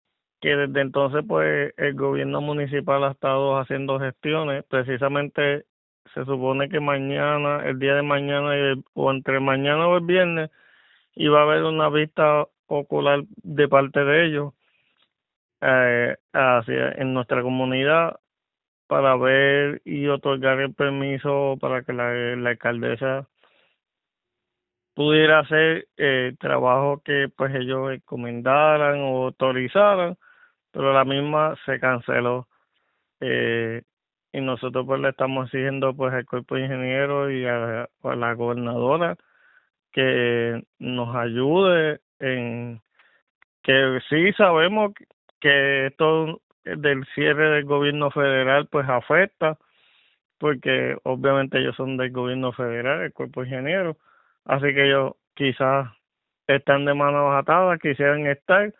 Sí sabemos que esto del cierre del Gobierno federal, pues, afecta porque, obviamente, ellos son del Gobierno federal […] así que ellos, quizá, están de manos atadas“, señaló en entrevista con Radio Isla.